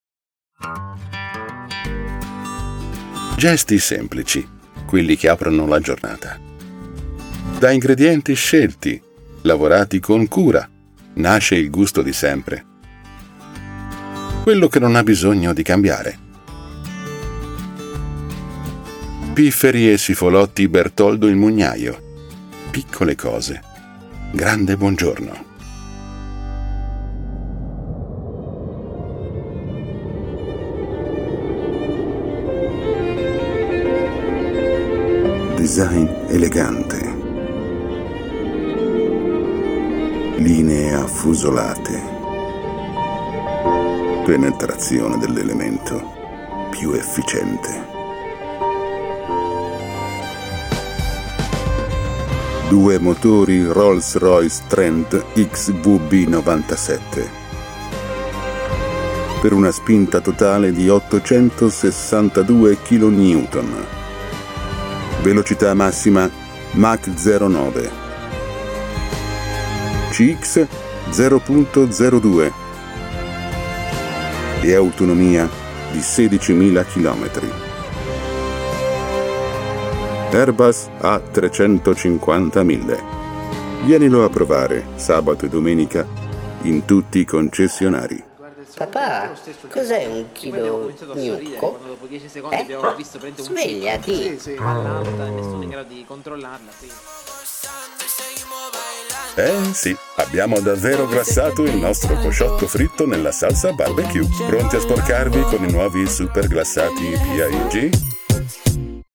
La mia voce è baritonale, calda e musicale: matura, chiara e naturale, con un tono accogliente ma deciso, ideale per progetti che richiedono credibilità e presenza.
Sprechprobe: eLearning (Muttersprache):